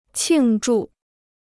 庆祝 (qìng zhù) Dicionário de Chinês gratuito